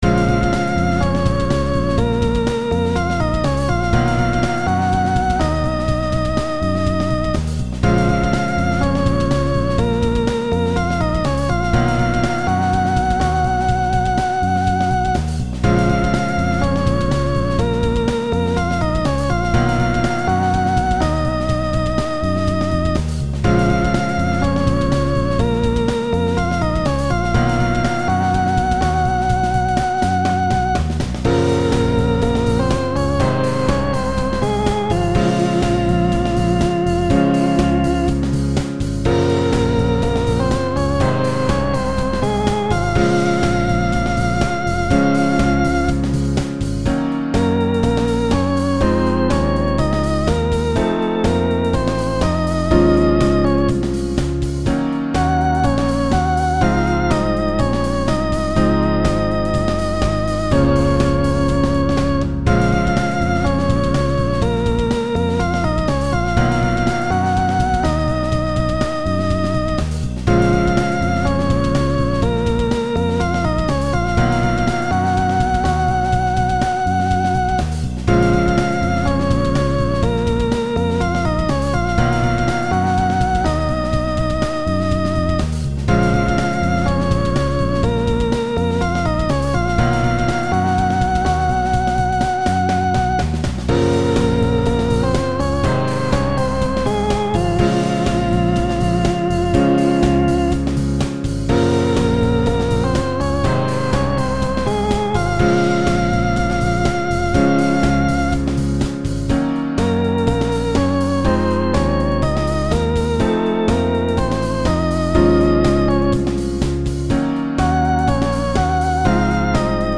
今日はグルーヴを既成のものから選んで、ベースを打ち込んで、ギターを弾いて録音して、メロディ打ち込んでボーカロイドで歌わせてみた。
相変わらずギター下手だ。
いまはまだ最小構成で作ってるわけだけど、日に日に構成数を大きくできるようにしていきたいね。